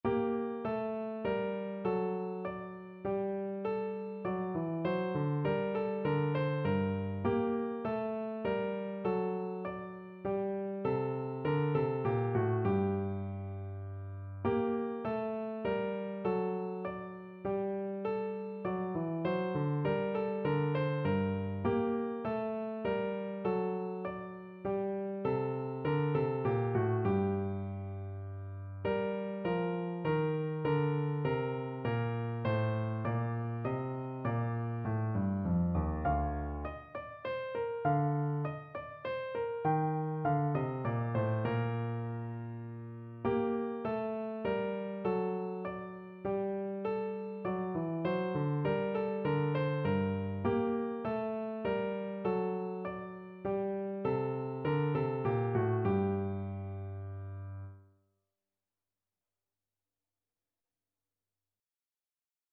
Piano version
No parts available for this pieces as it is for solo piano.
3/4 (View more 3/4 Music)
Piano  (View more Easy Piano Music)
Classical (View more Classical Piano Music)